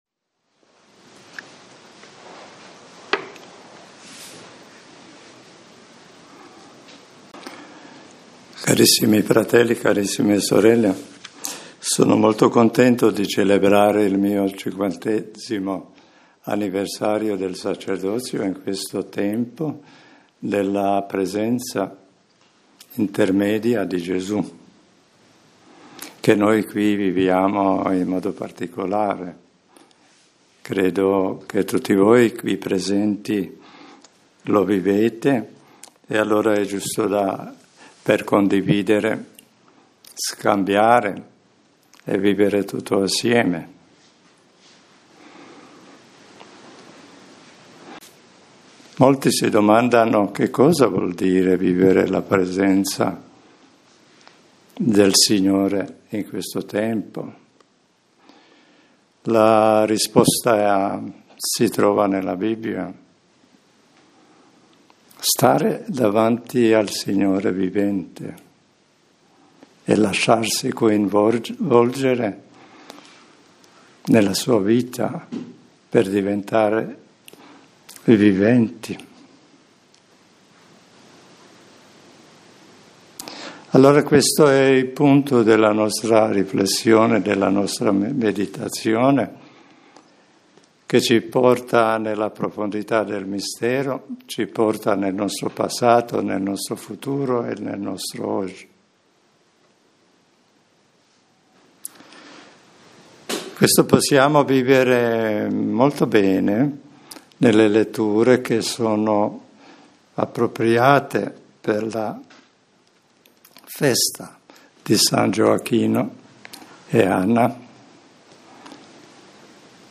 Riflessione – Partecipare alla presenza del Cristo Vivente - Chiesa di Gesù Cristo dell'Universo